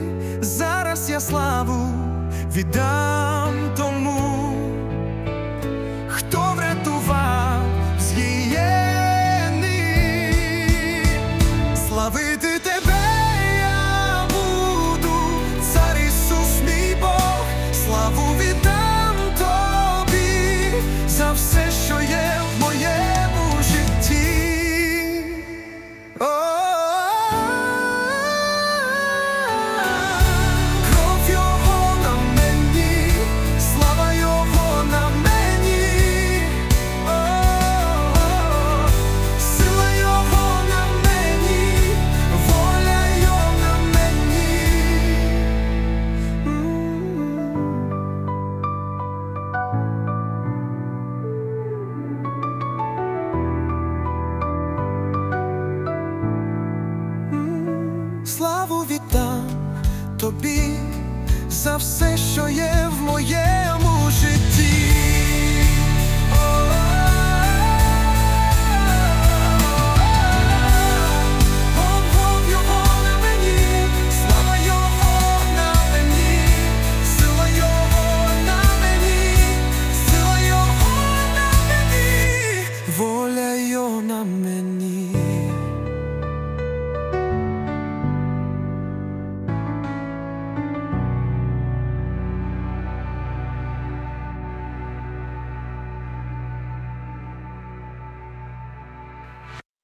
103 просмотра 123 прослушивания 5 скачиваний BPM: 185